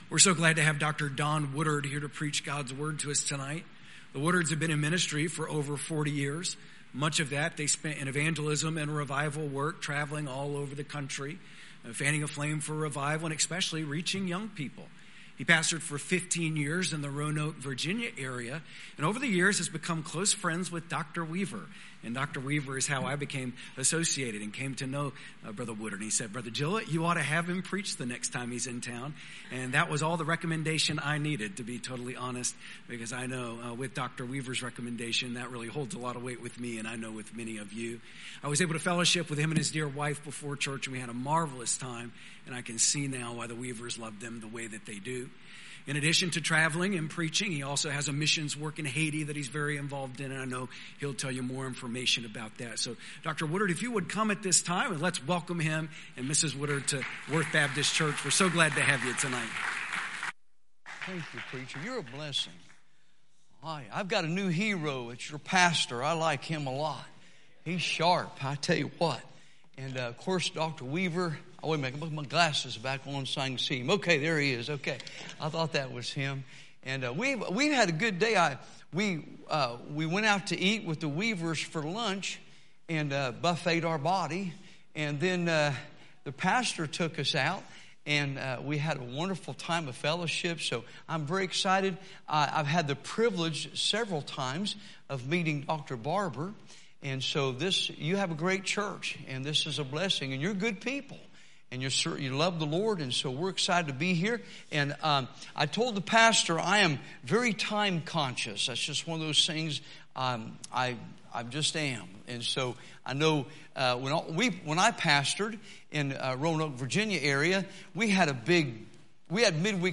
Visiting Preachers